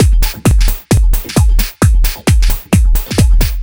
132 Skip N Groove No Snr.wav